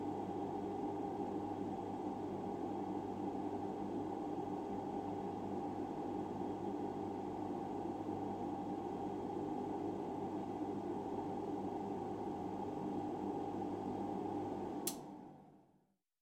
KGcoAGDxnBa_MACHFan-Ventilation-hotte-2-ID-0062-LS.mp3